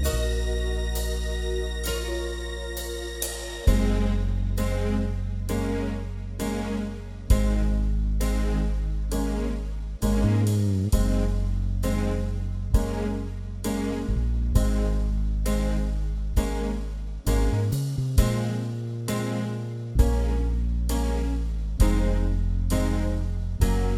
Two Semitones Down Rock 6:21 Buy £1.50